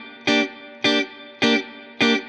DD_StratChop_105-Gmin.wav